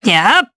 Valance-Vox_Attack7_jp.wav